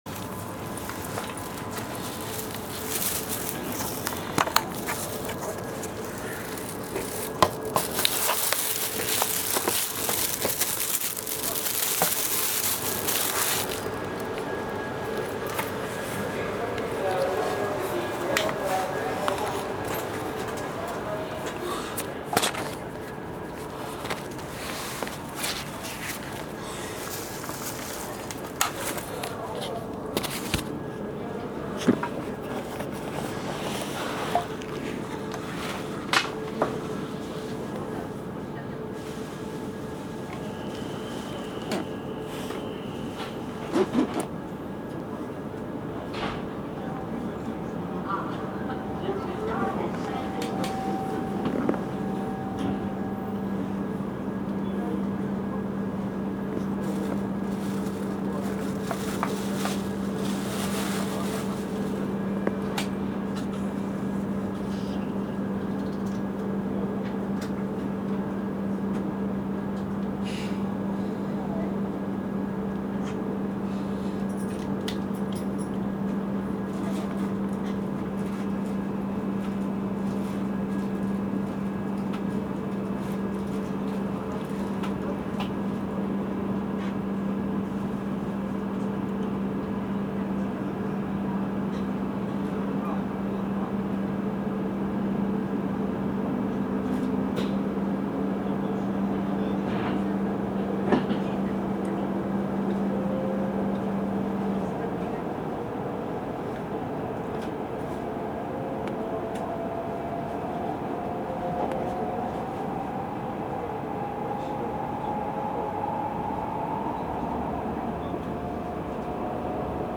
写真と走行音でE5系を紹介するページです。
走行音